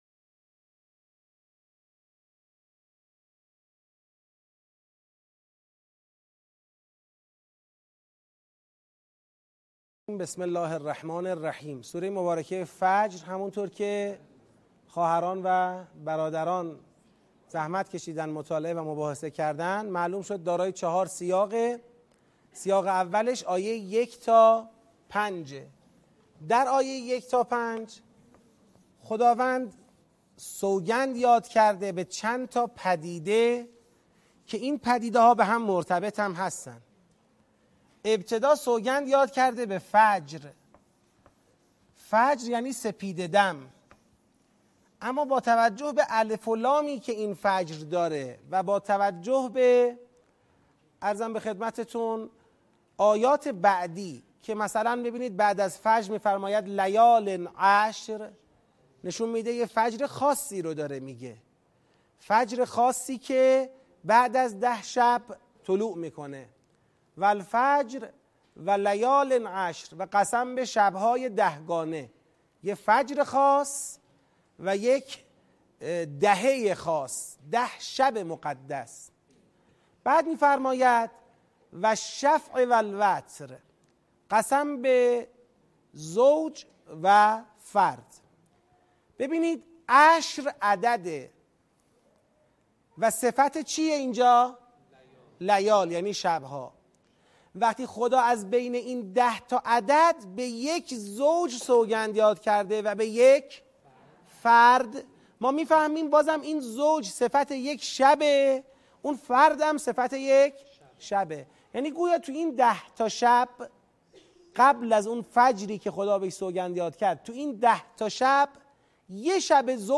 آموزش تدبر در سوره فجر - بخش دوم